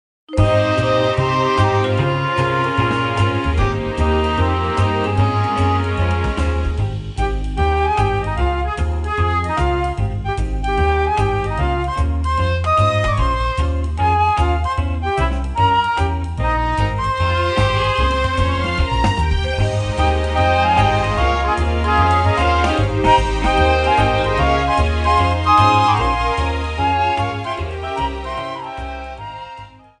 Clipped to 30 seconds and applied fade-out